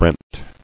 [brent]